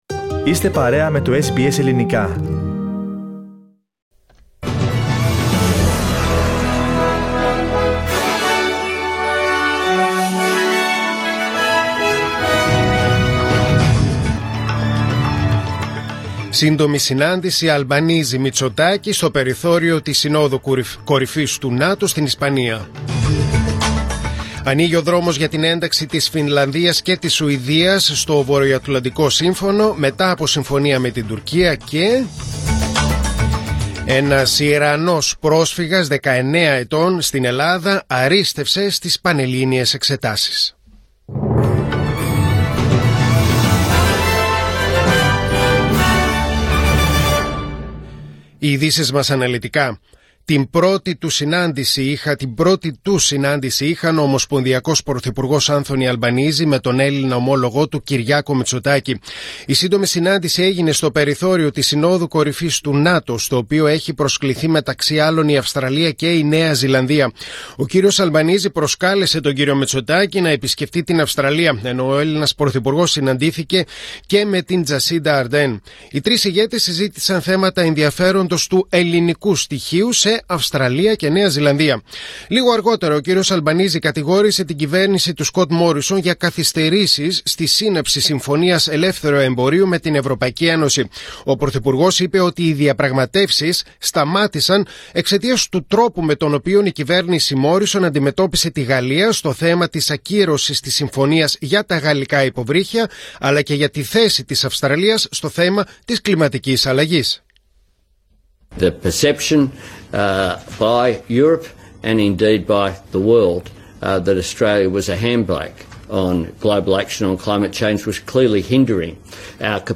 Δελτίο Ειδήσεων: Τετάρτη 29.6.2022